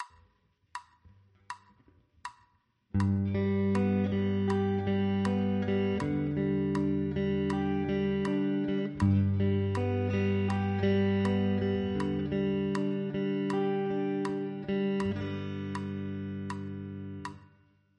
Ex 6 – classic picking II